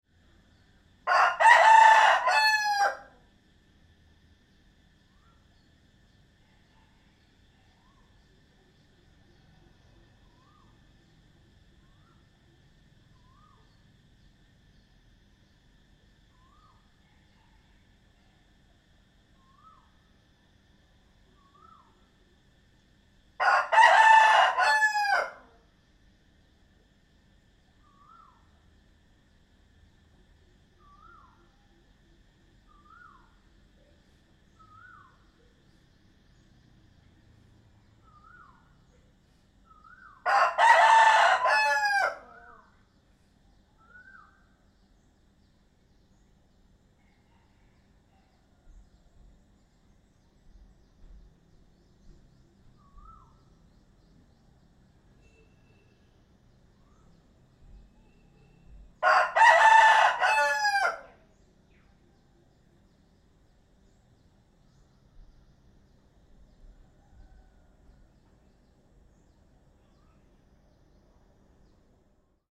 دانلود صدای آواز خروس در شب از ساعد نیوز با لینک مستقیم و کیفیت بالا
جلوه های صوتی